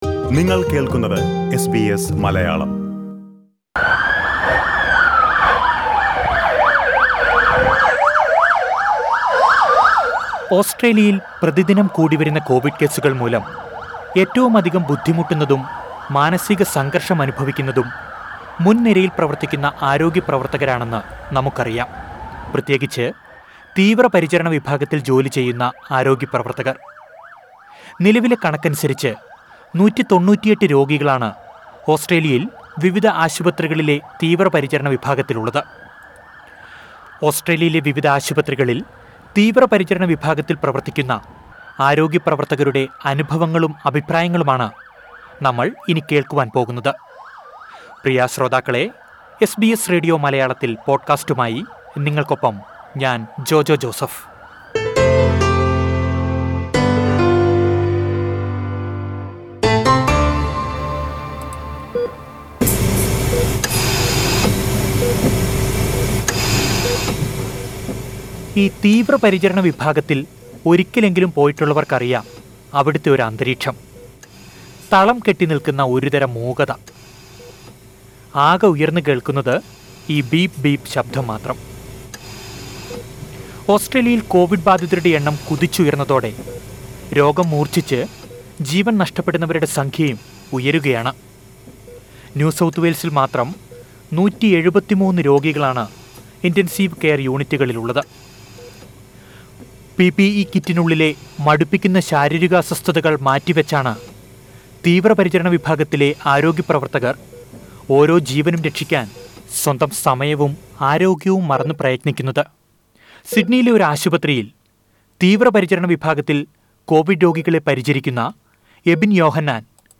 Listen to Nurses describe their experiences and situations in Australian ICUs during the pandemic.